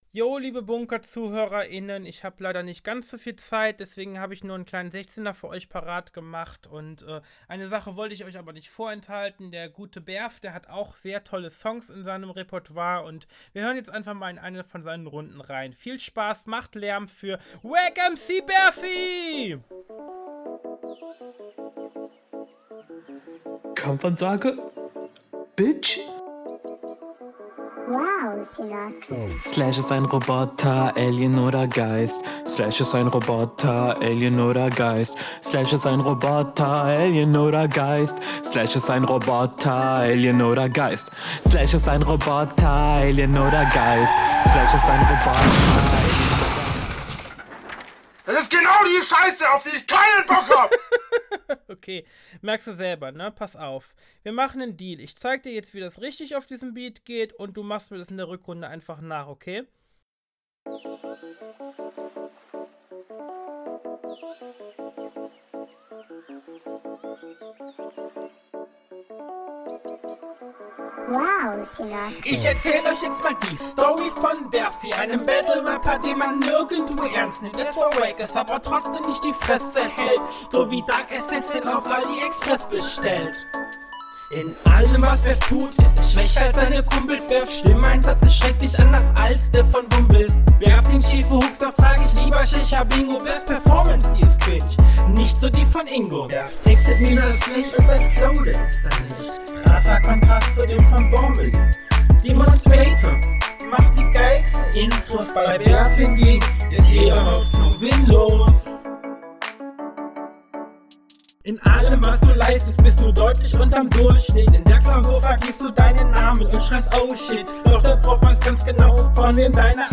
Flow: Der Flow ist der beste derzeit und gefällt mir sehr gut ist aber ausbaufähig …